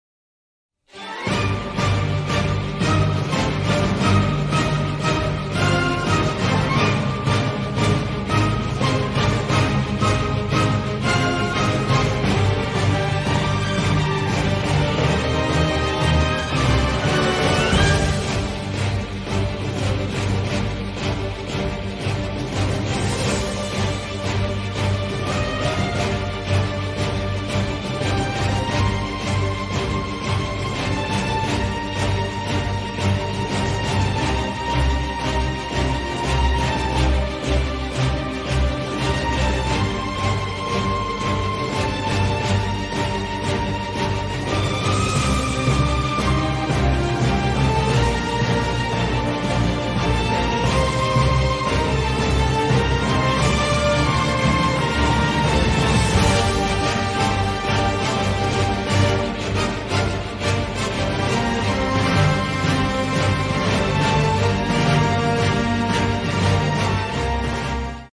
Battle Theme